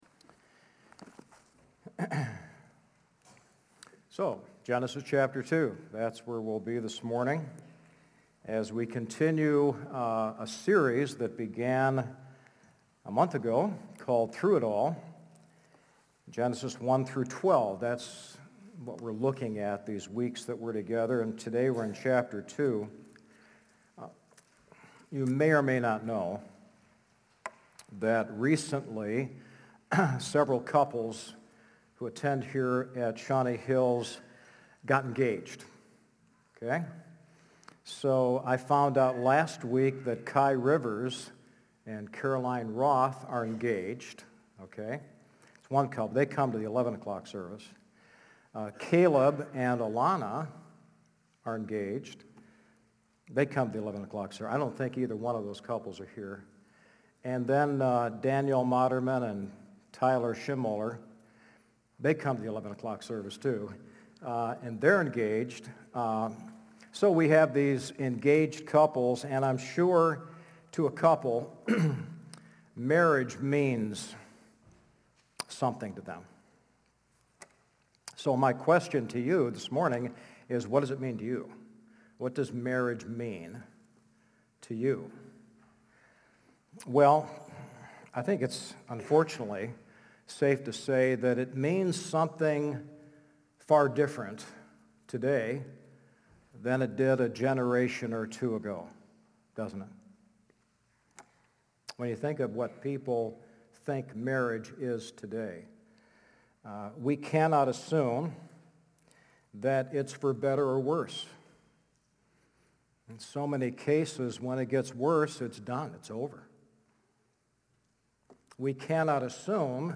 The Marriage Model | Baptist Church in Jamestown, Ohio, dedicated to a spirit of unity, prayer, and spiritual growth